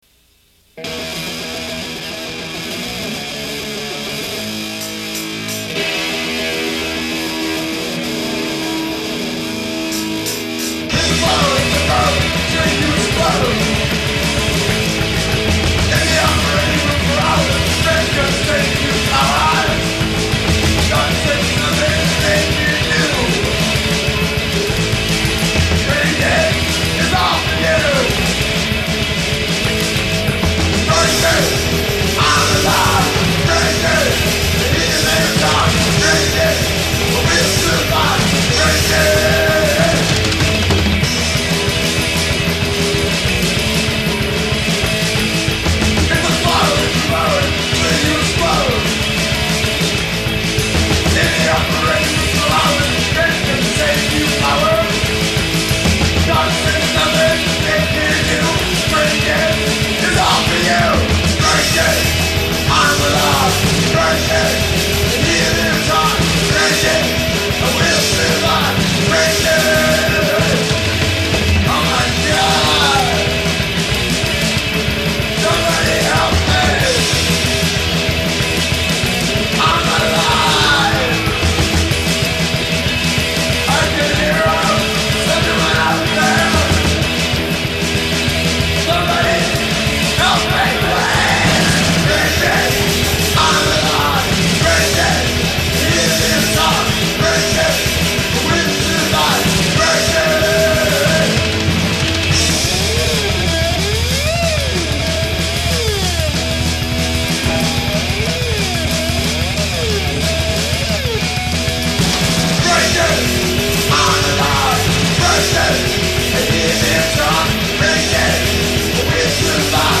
These songs are from their demo tape.